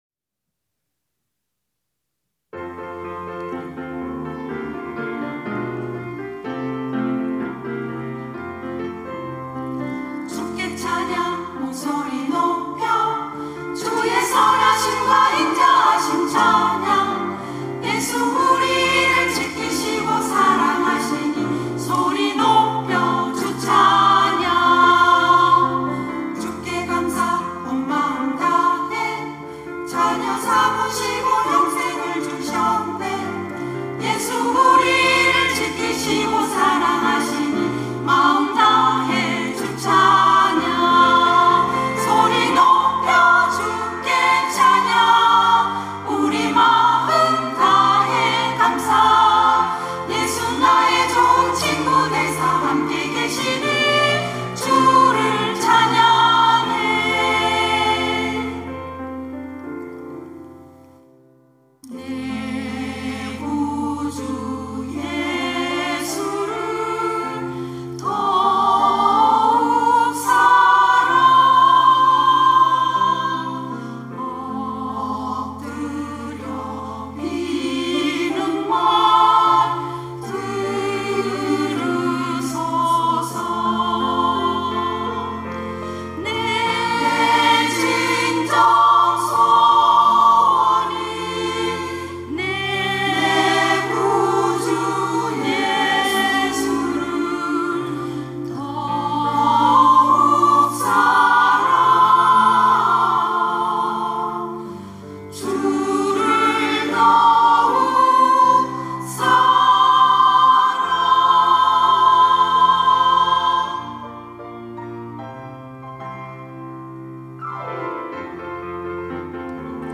특송과 특주 - 주께 찬양
천안중앙교회
이름 권사